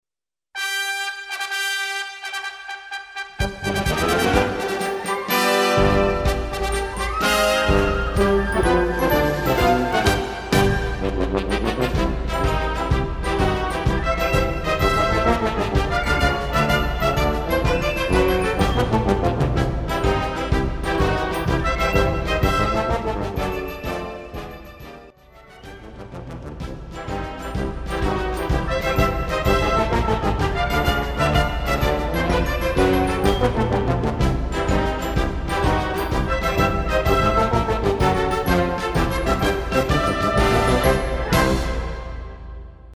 内容解説 テレビニュースなどでおなじみのマーチです
編成内容 大太鼓、中太鼓、小太鼓、シンバル 作成No ０９１